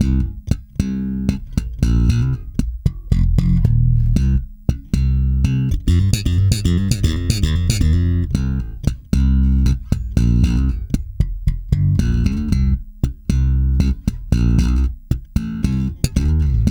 -JP THUMB C.wav